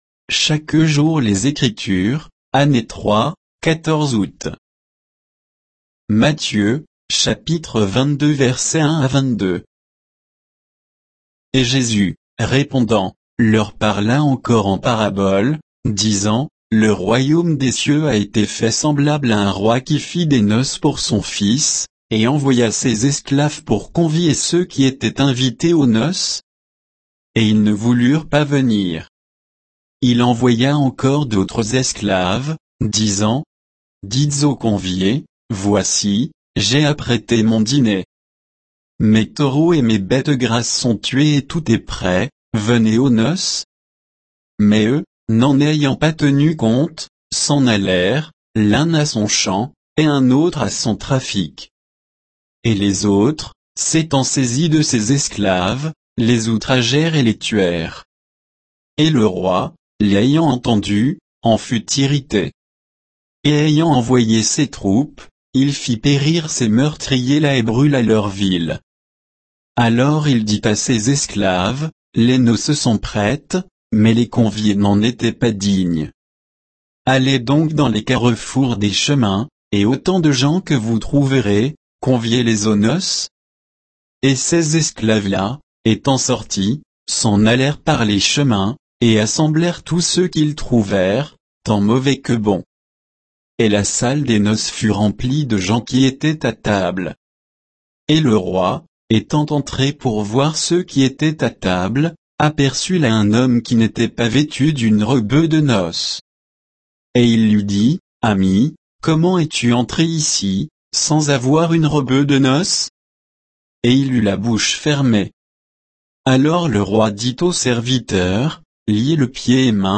Méditation quoditienne de Chaque jour les Écritures sur Matthieu 22, 1 à 22